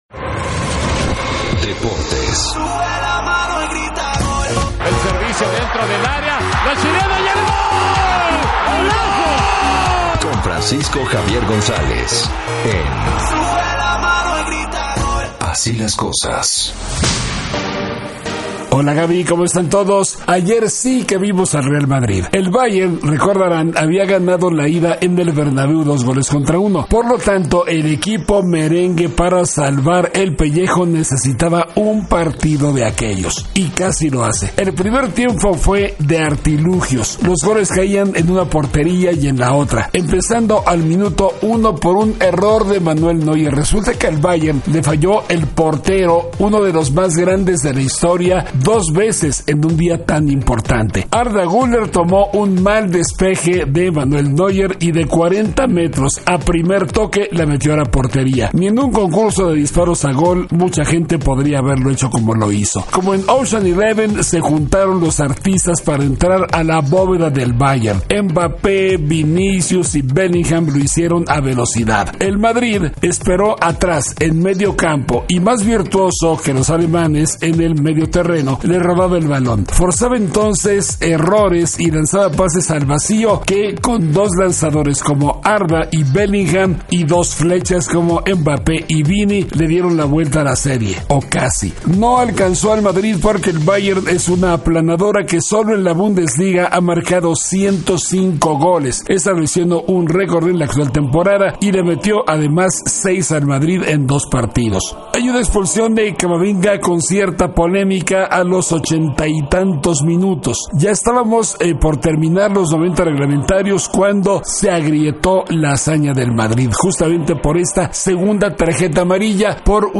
periodista y comentarista deportivo